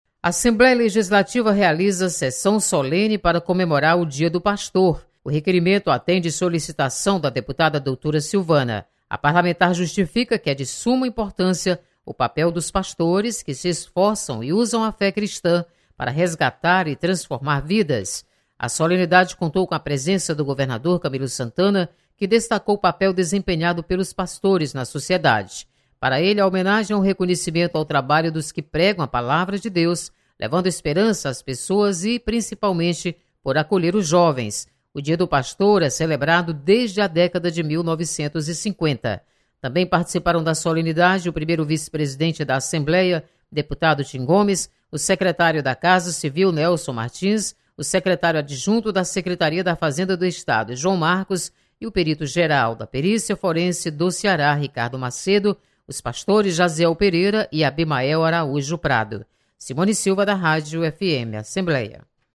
Assembleia realiza sessão solene em comemoração ao Dia do Pastor. Repórter